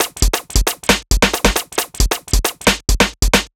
Lazik Break 135.wav